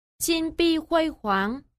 Jīnbìhuīhuáng.
chin bi huây hoáng